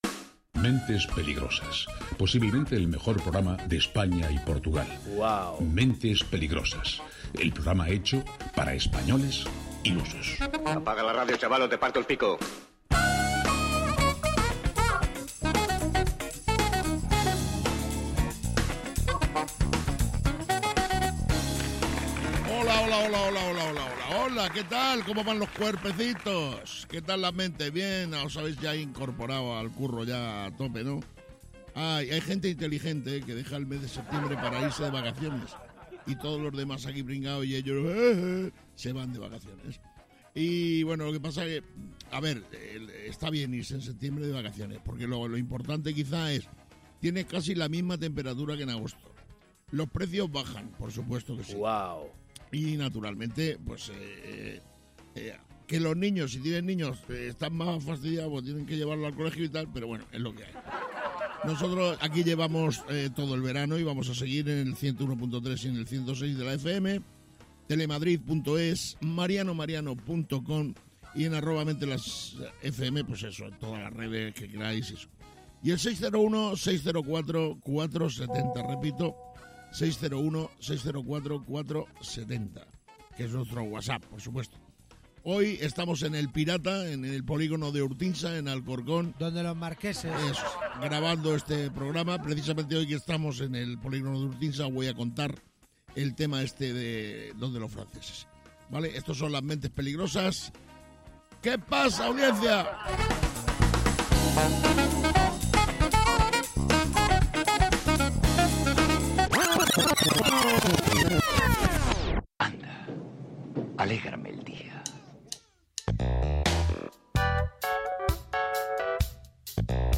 Mentes Peligrosas de Mariano Mariano es un programa de radio en el que cada día se presenta una nueva aventura o no, depende siempre del estado anímico de los participantes en el mismo. ¿Es un programa de humor?